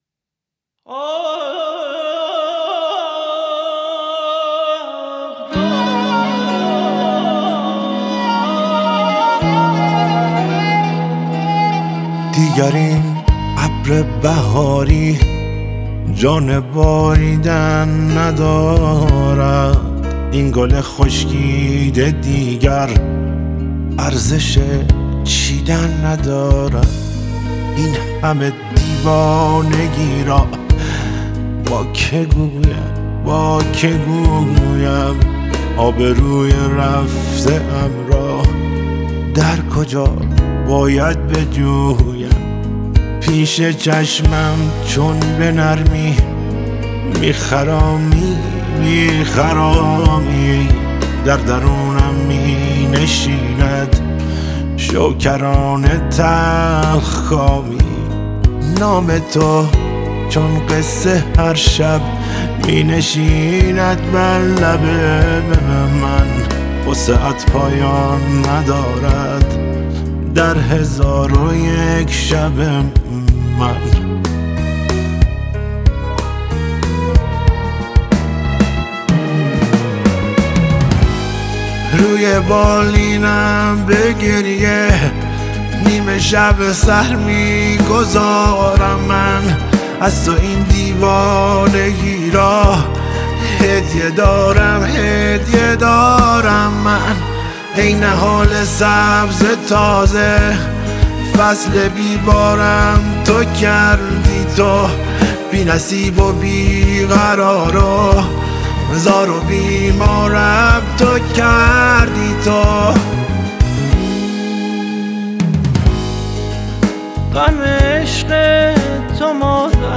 ملودی احساسی